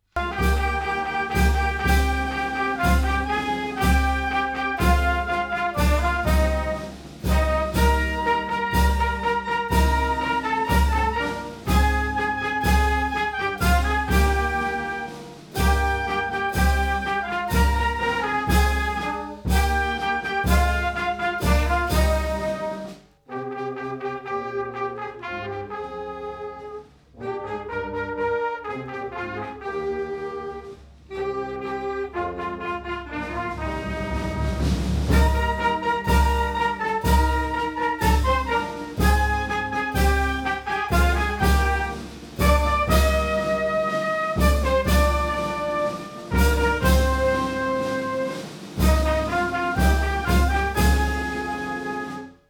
National Anthem tune.wav